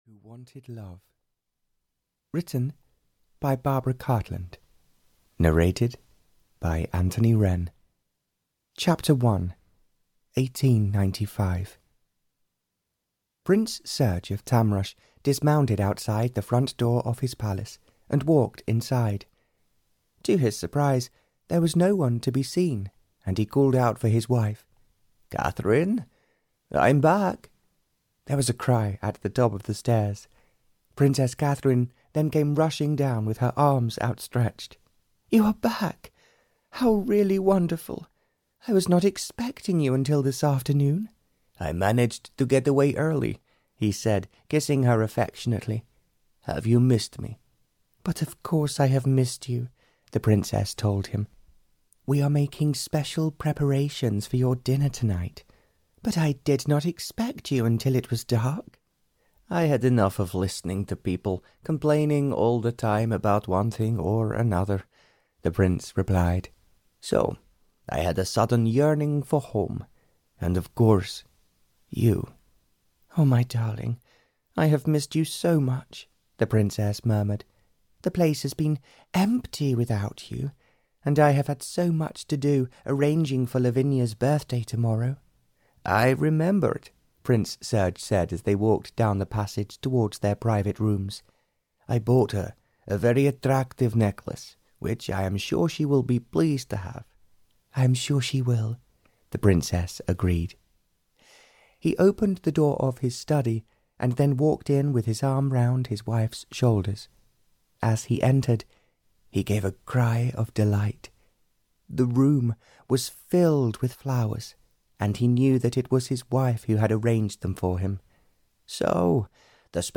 Audio knihaThe Prince Who Wanted Love (Barbara Cartland's Pink Collection 139) (EN)
Ukázka z knihy